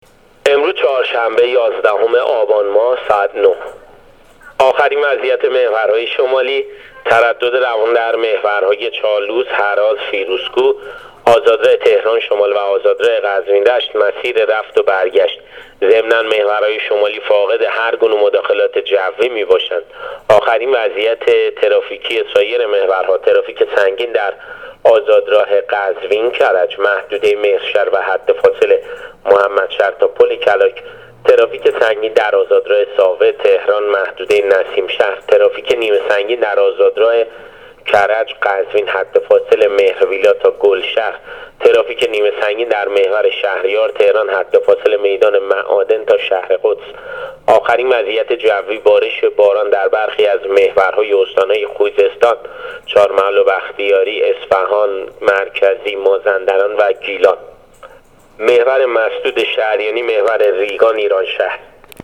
گزارش رادیو اینترنتی از آخرین وضعیت ترافیکی جاده‌ها تا ساعت ۹ یازدهم آبان؛